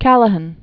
(kălə-hən, -hăn), (Leonard) James 1912-2005.